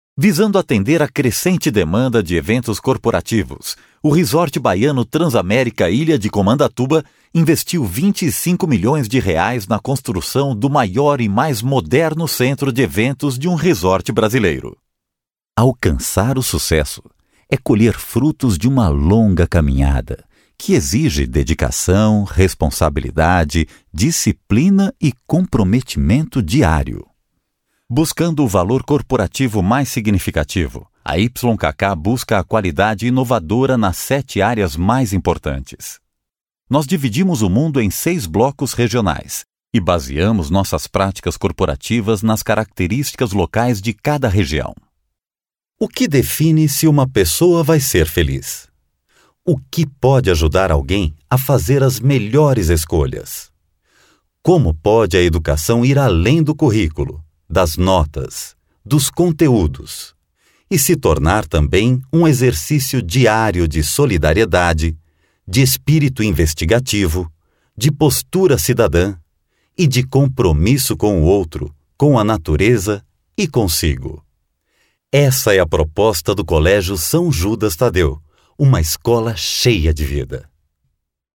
Masculino
Com o estúdio montado em casa, acústica bem cuidada e equipamentos de primeira linha, posso garantir o ótimo nível de qualidade técnica do trabalho.